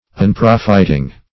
unprofiting.mp3